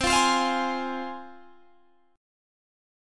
Csus4#5 Chord
Listen to Csus4#5 strummed